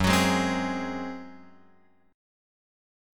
F#sus2b5 chord {2 x 4 1 1 4} chord